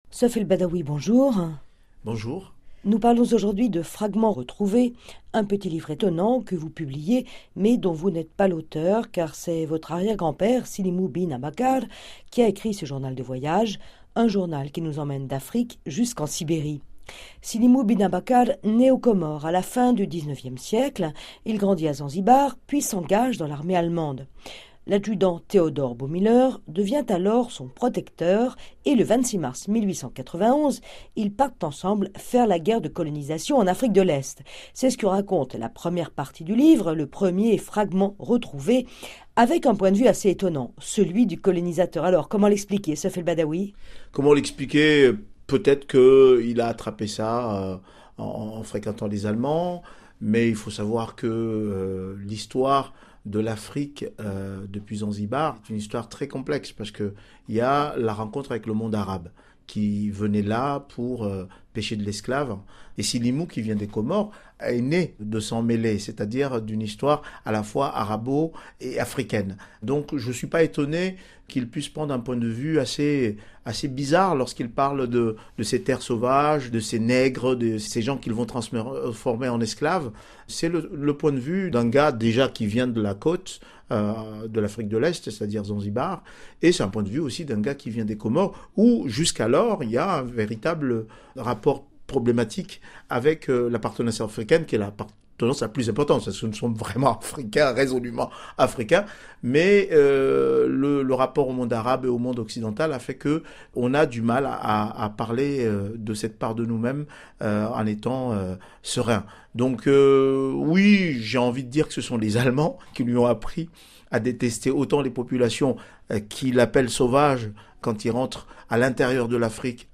Extrait d’un entretien